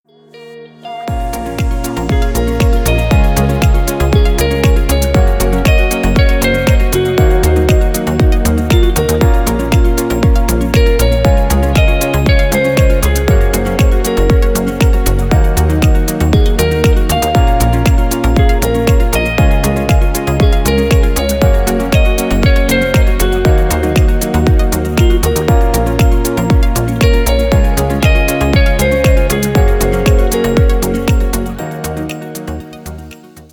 Рингтоны спокойных мотивов
• Качество: Хорошее
• Песня: Рингтон, нарезка